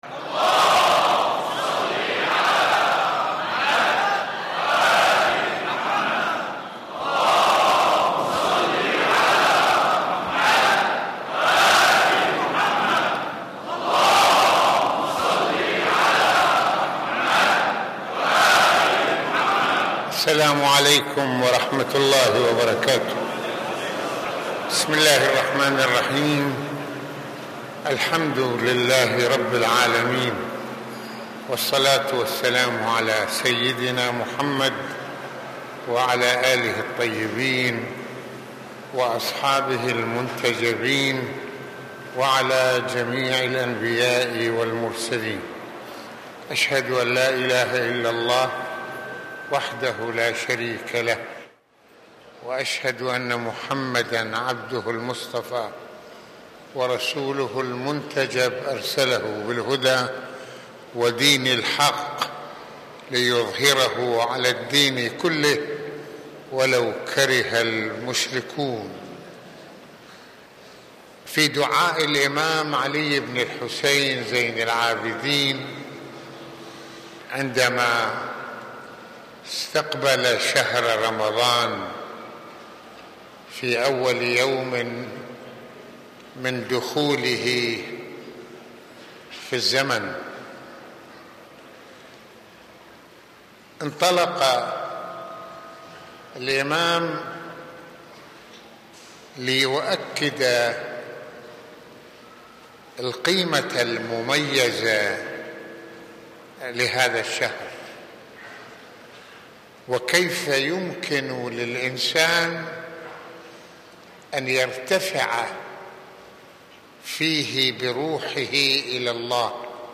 شهر رمضان ساحة الإسلام بكل مفرداته ومعانيه | محاضرات رمضانية